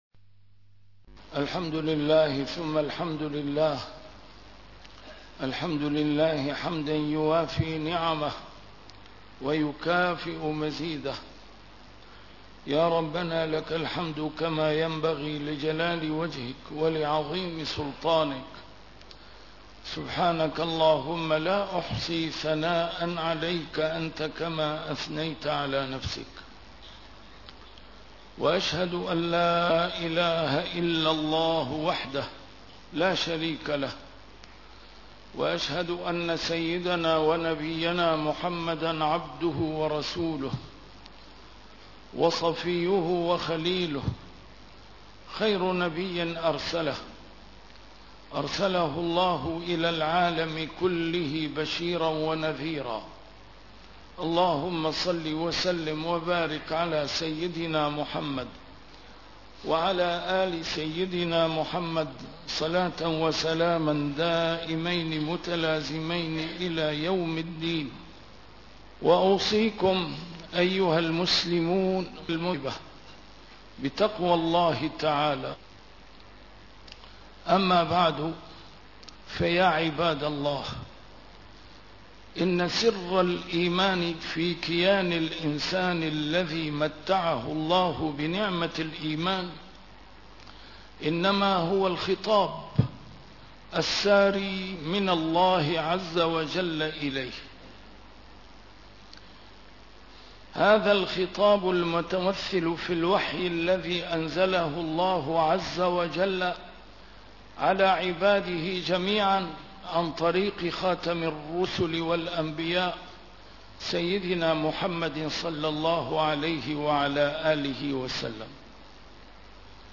A MARTYR SCHOLAR: IMAM MUHAMMAD SAEED RAMADAN AL-BOUTI - الخطب - كيف نعظم كتاب الله تعالى؟